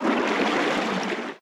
Sfx_creature_penguin_swim_glide_03.ogg